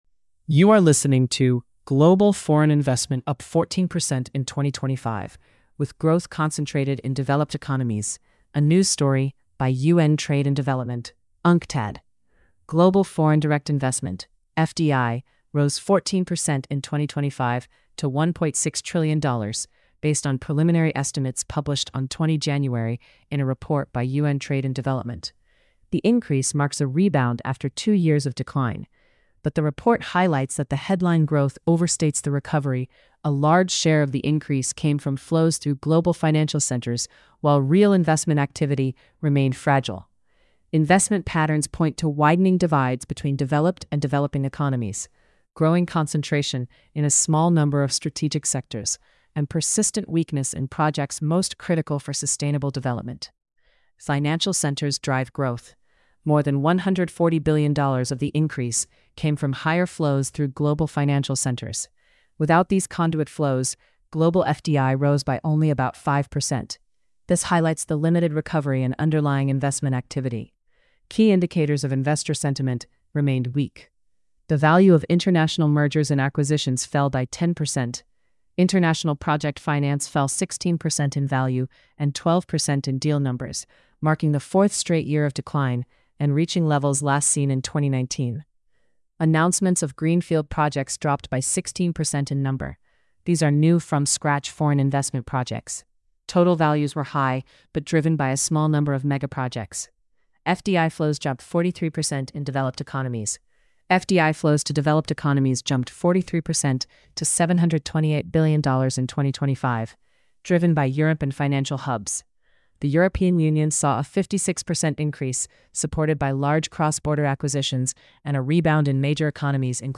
Narrated by AI. Inconsistencies may occur.